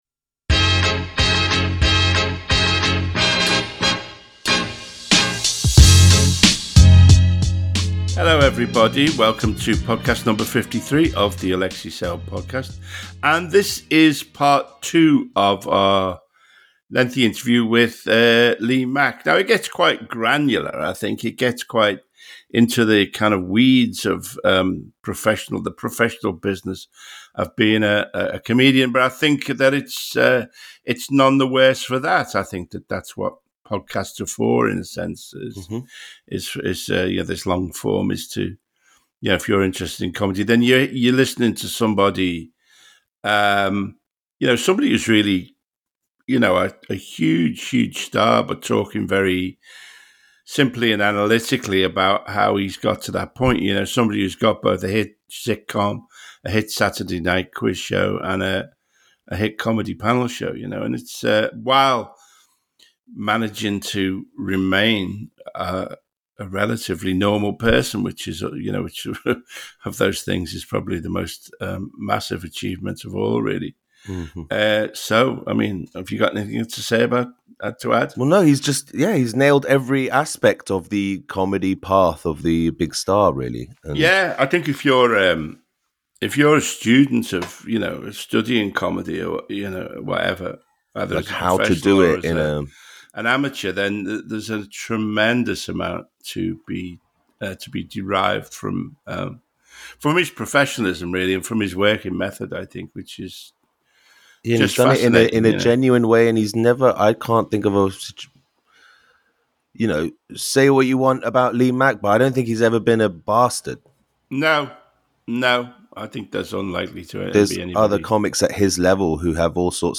Part 2 of Alexei's conversation with Lee Mack dives deeper into Lee's TV career and his journey to becoming a household name.